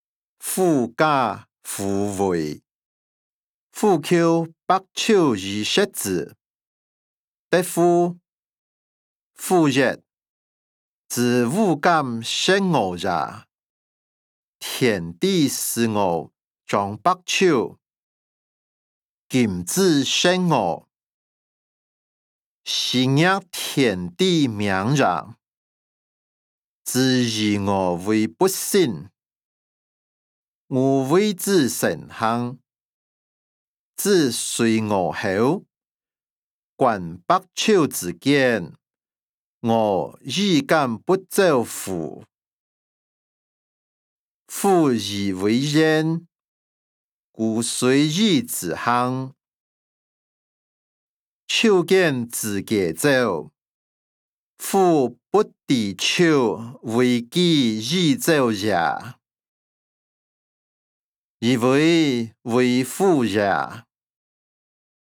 歷代散文-狐假虎威音檔(饒平腔)